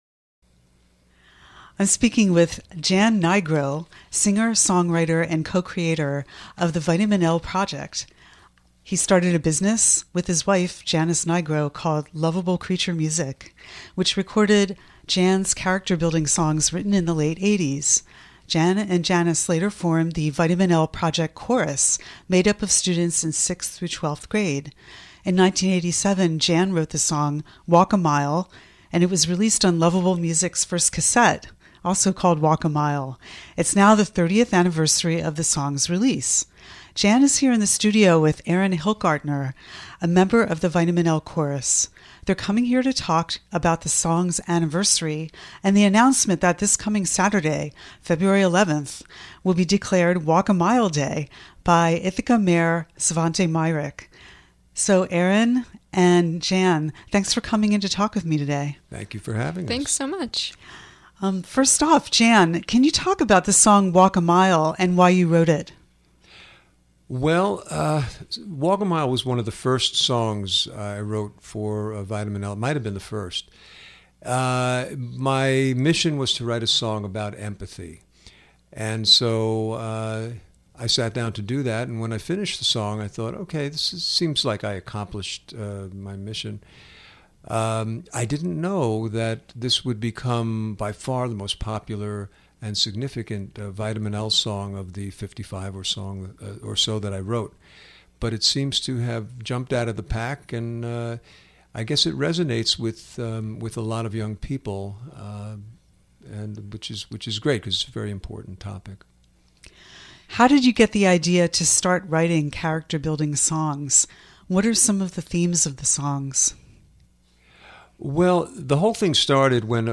This interview aired on the February 7th, 2017 edition of WRFI Community Radio News.